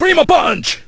el_primo_atk_02.ogg